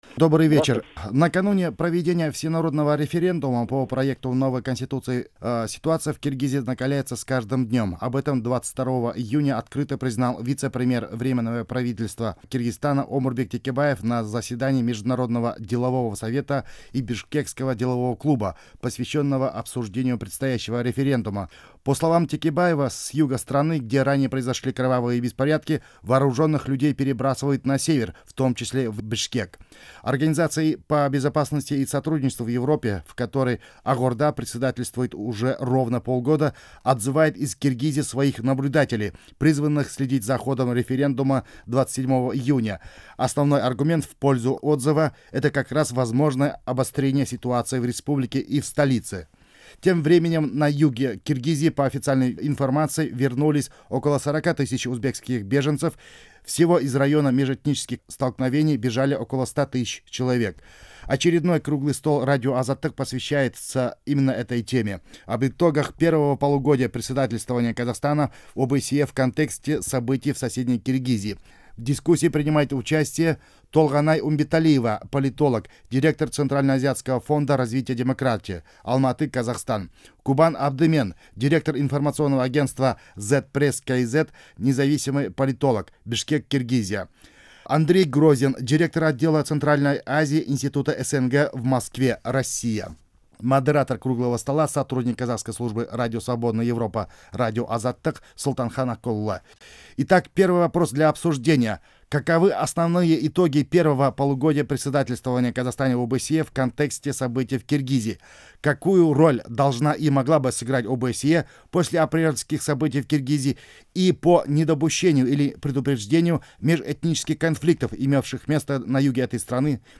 Дөңгелек үстел сұқбатын тыңдаңыз